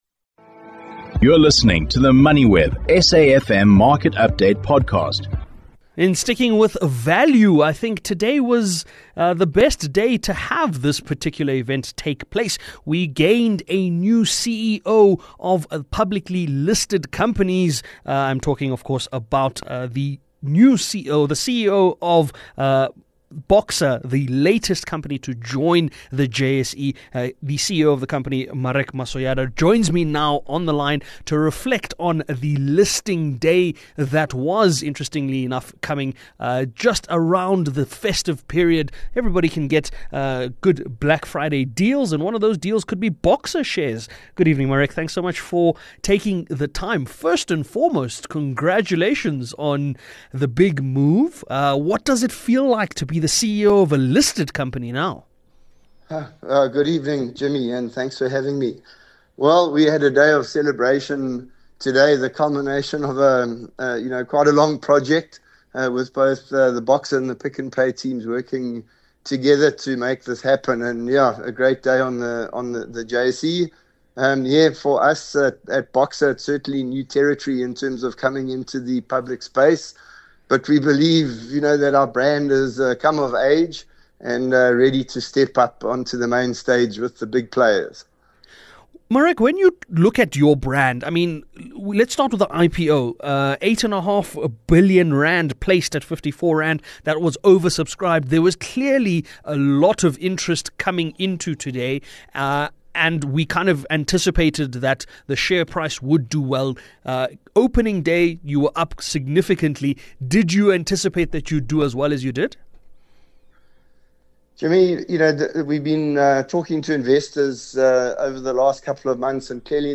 The programme is broadcasted Monday to Thursday nationwide on SAfm (104 – 107fm), between 18:00 and 19:00.